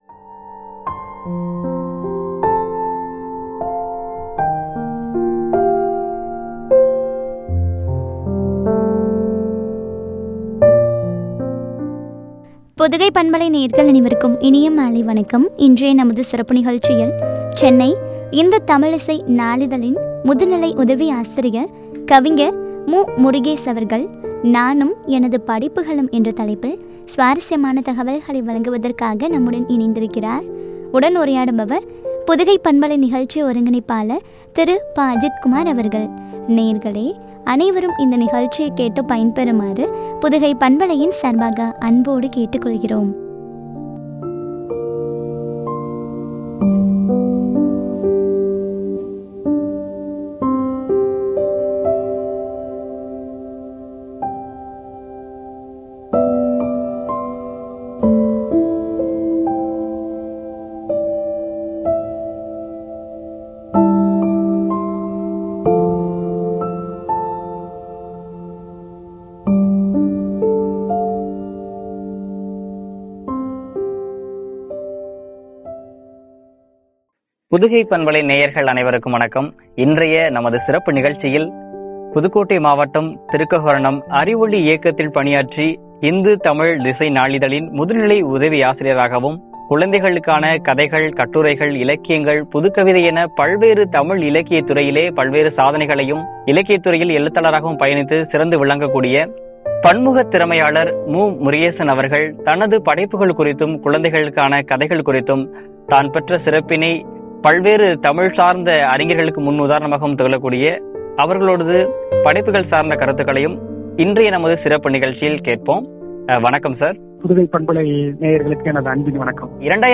எனது படைப்புகளும் என்ற தலைப்பில் வழங்கிய உரையாடல்.